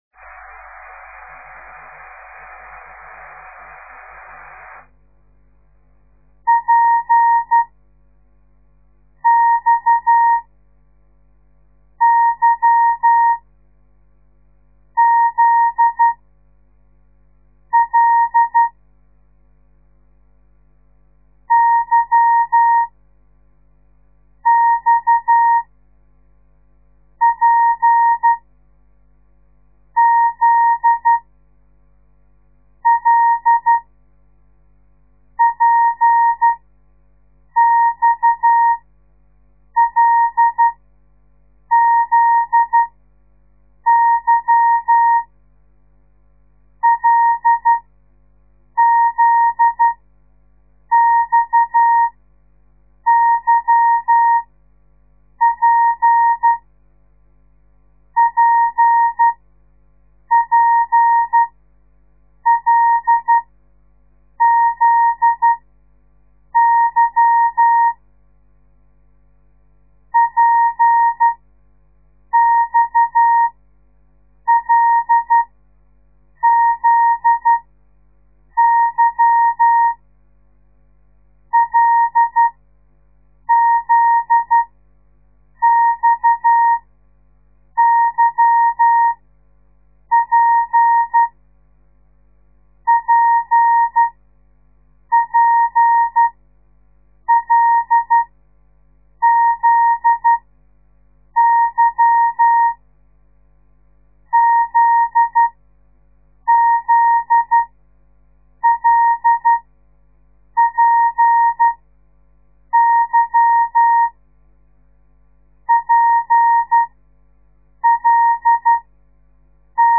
De geluidsfiles bestaan uit geseinde text in letter of cijfergroepen , steeds groepen van 5 tekens en iedere les bestaat uit 25 groepen
De letter P di-dah-dah-dit / de letter X Dah-di-di-dah / de letter Y dah-di-dah-dah / de letter Z dah-dah-di-dit / de letter L di-dah-di-dit